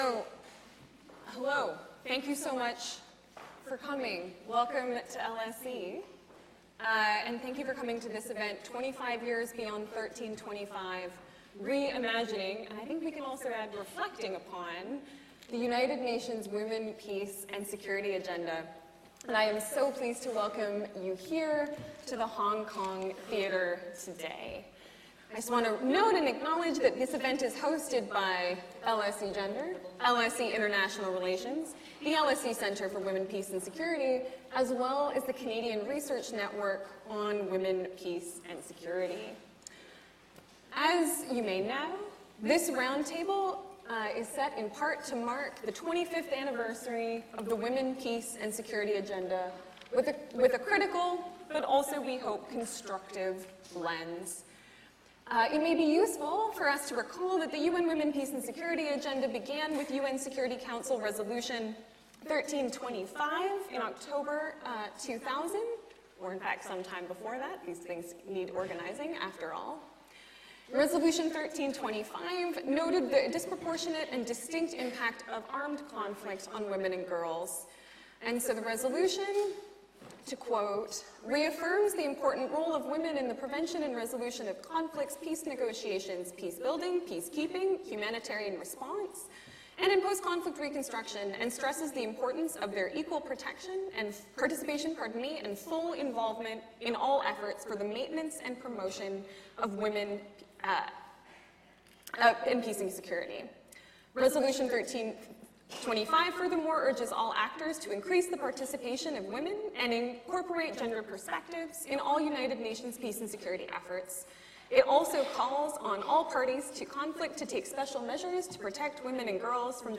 Listen to or download podcasts from our 2025 public events at the Department of International Relations at LSE